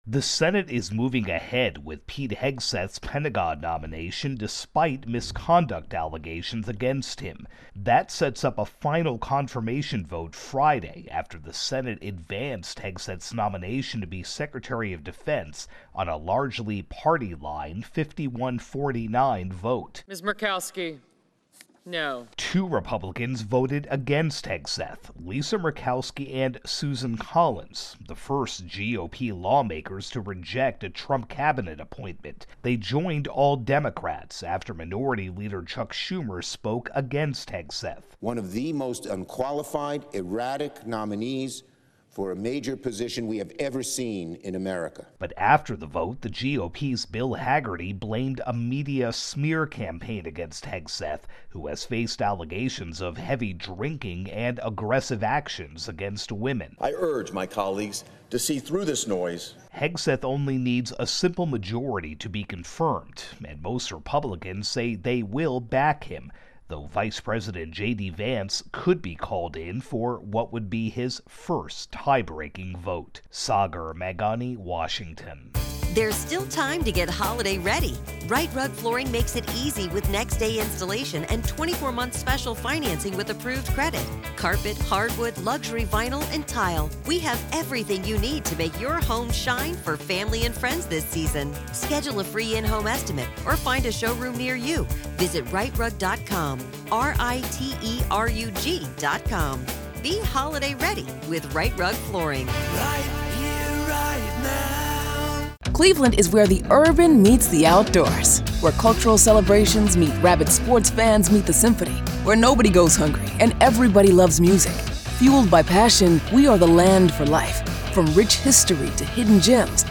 reports on the Senate advancing Pete Hegseth's nomination to be defense secretary.